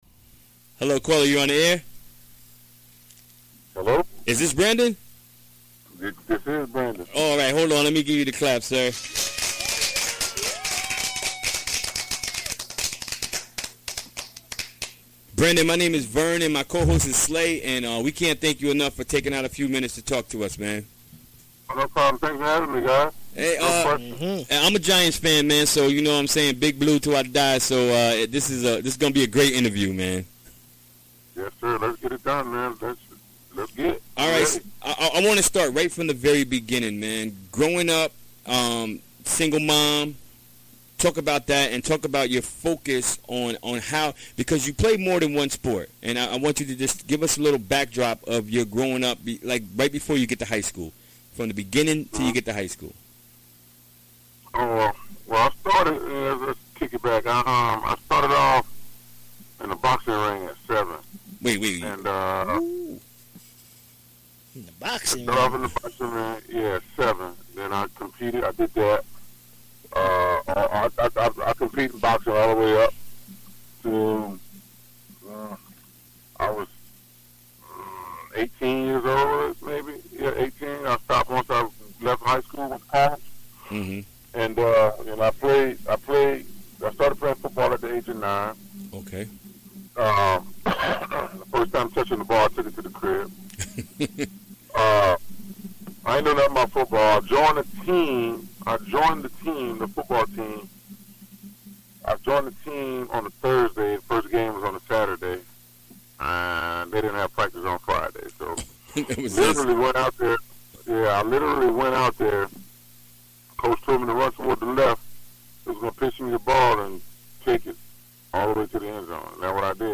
Recorded during the WGXC Afternoon Show Wednesday, February 21, 2018.